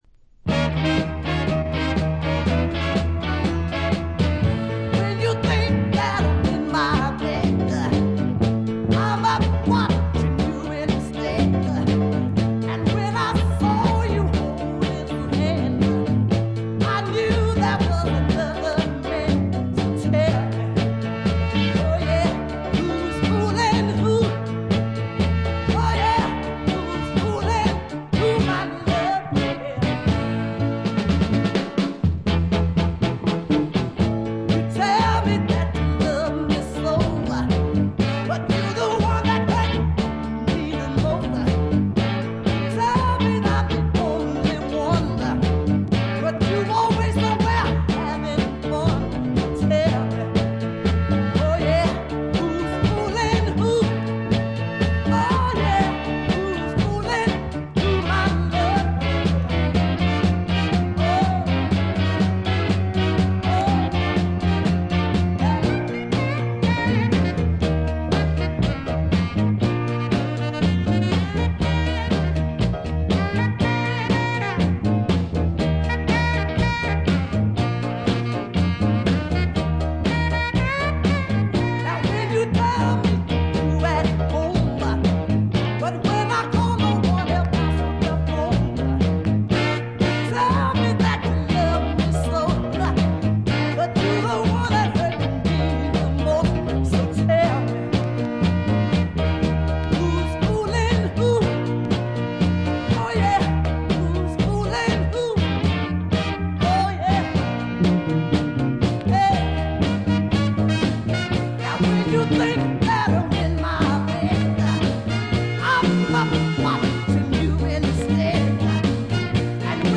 A2の終盤2割ぐらいとA3の前半4割ぐらいに傷がありプツ音を周回気味に発生します。
モノプレス盤。
試聴曲は現品からの取り込み音源です。
※ノイズ確認用。A3-4連続です。